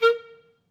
Clarinet
DCClar_stac_A#3_v3_rr2_sum.wav